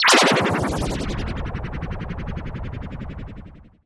SI2 SPLOOSH.wav